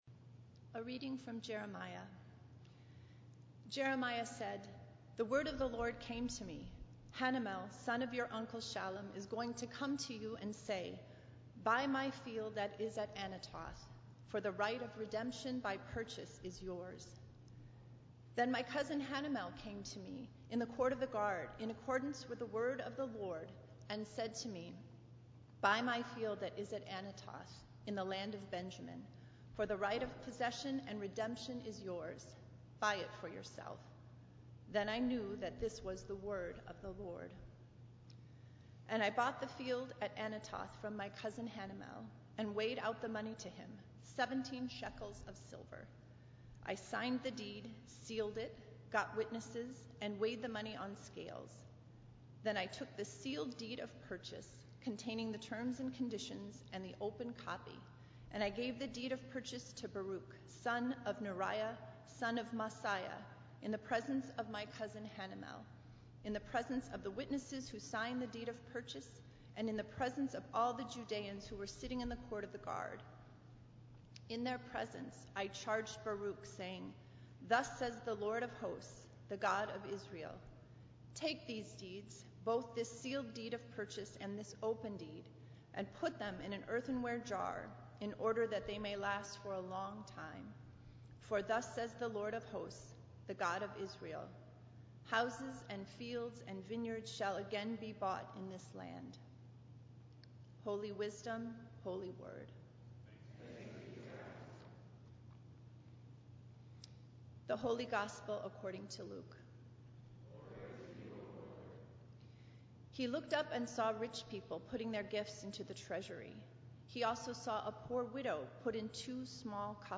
Minnetonka Livestream · Sunday, July 10, 2022 9:30 am